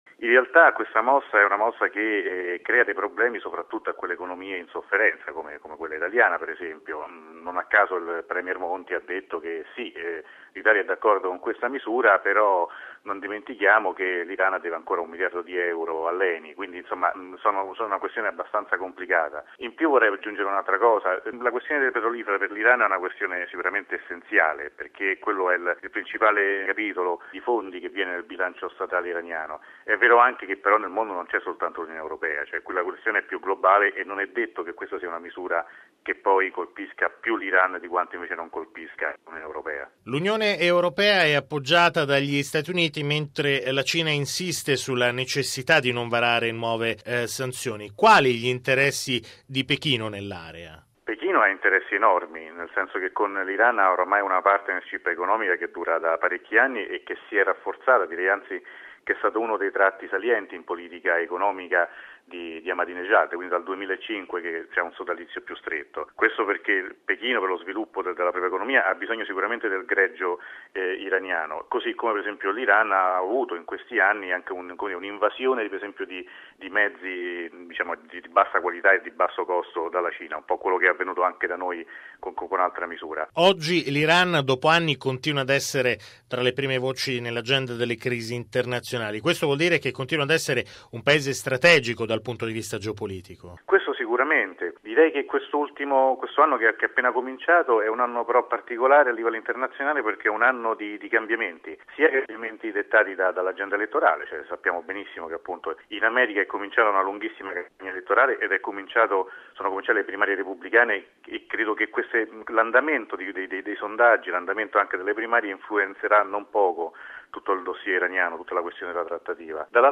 esperto di politica iraniana